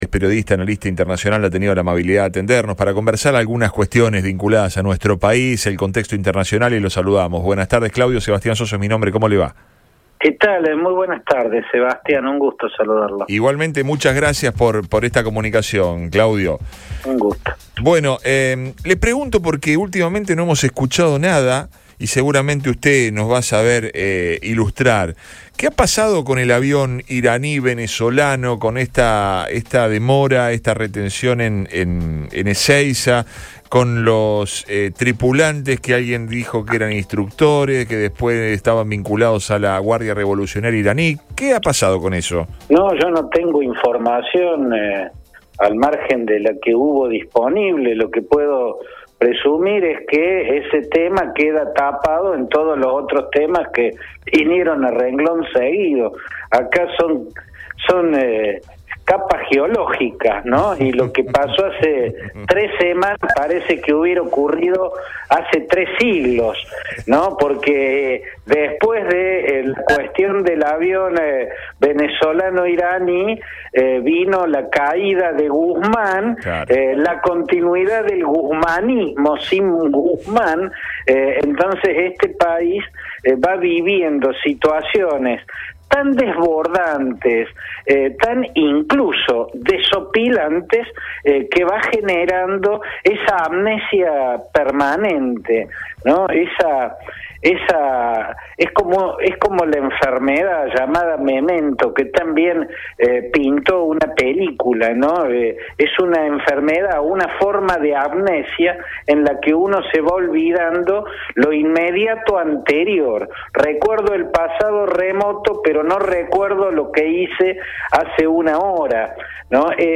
Tras la salida de Martín Guzmán y la designación de Silvina Batakis como ministra de Economía, Claudio Fantini se presentó en Radio Boing para hablar al…